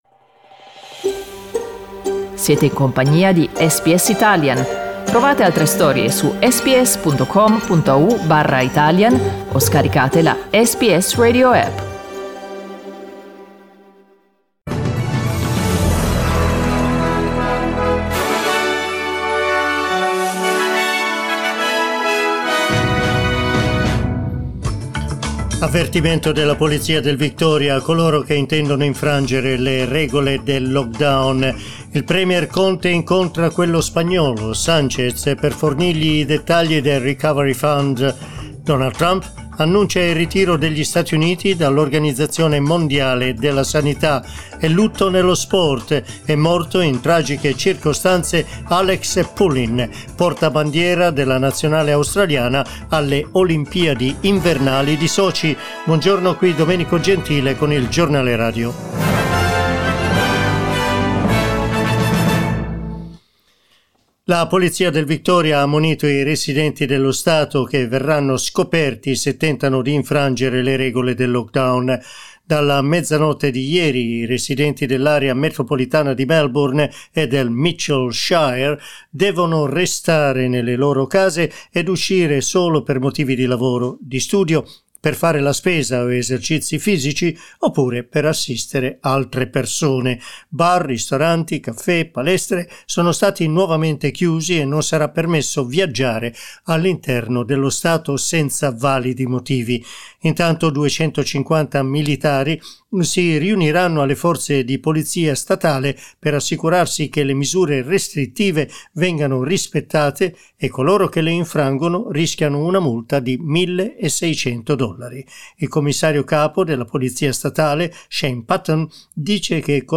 Our news bulletin (in Italian)